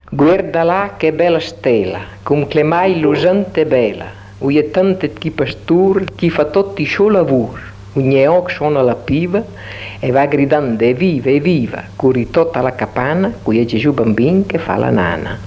filastrocca - il presepe.mp3